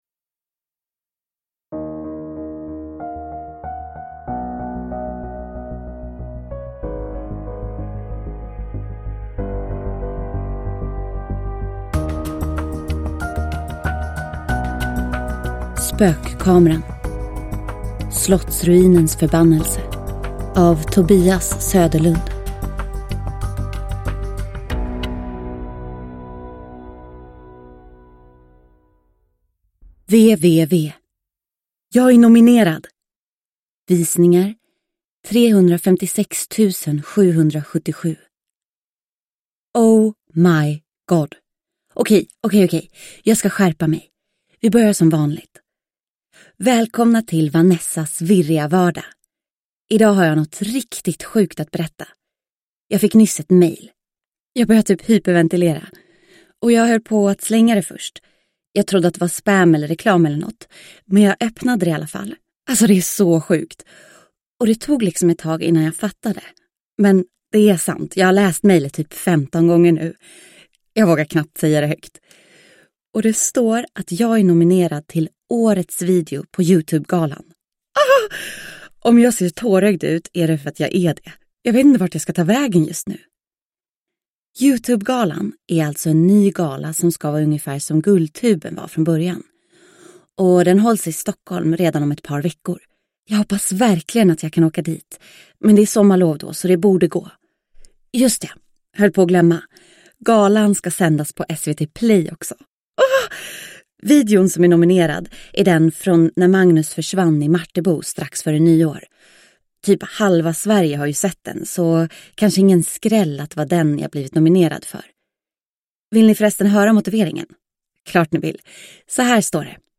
Slottsruinens förbannelse – Ljudbok – Laddas ner